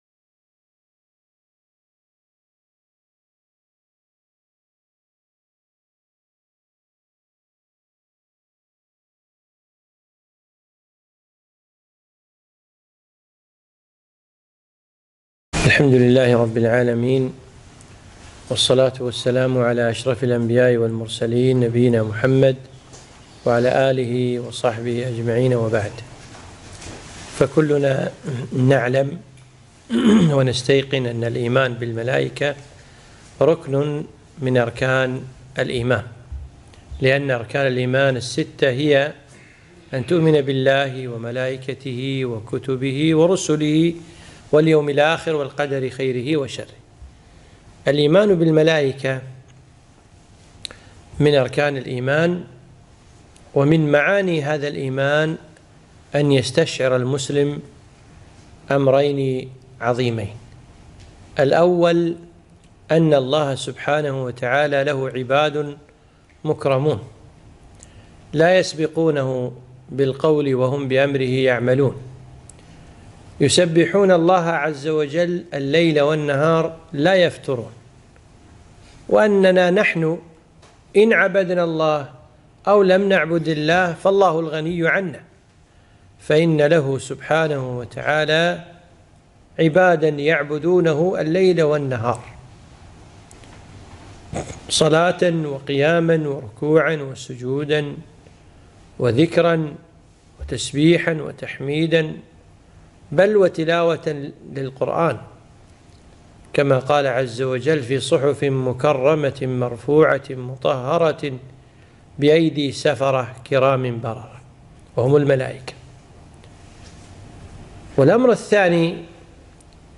محاضرة - كيف نحصل دعاء الملائكة ؟